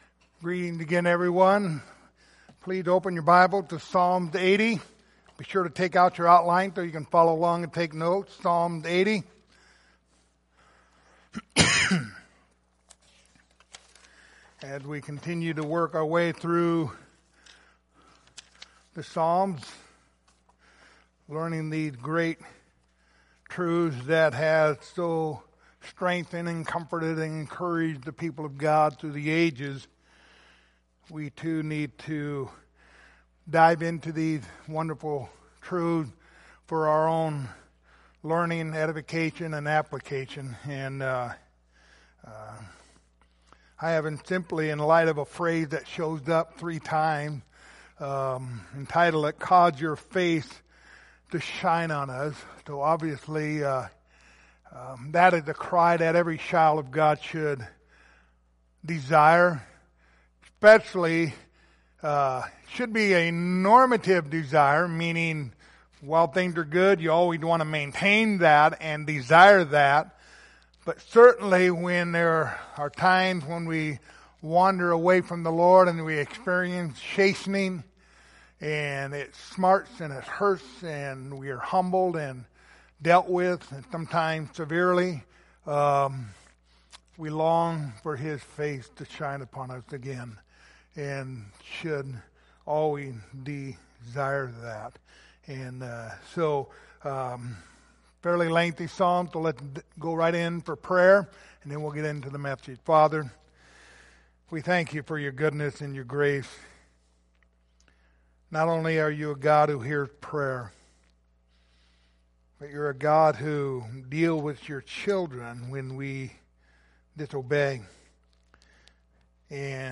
Passage: Psalms 80:1-19 Service Type: Sunday Morning Topics